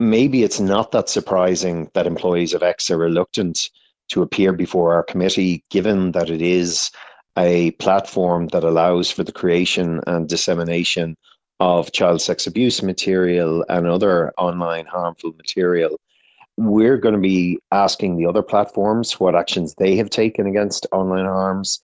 Committee member Malcolm Byrne says it’s clear why Elon Musk’s company isn’t up to the challenge…………..